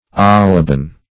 oliban - definition of oliban - synonyms, pronunciation, spelling from Free Dictionary Search Result for " oliban" : The Collaborative International Dictionary of English v.0.48: Oliban \Ol"i*ban\, n. (Chem.)